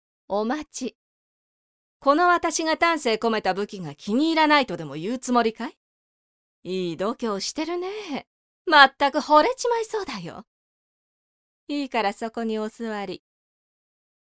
【サンプルセリフ】
ゆったりした大人の女性が良いなと思って設定させていただきました。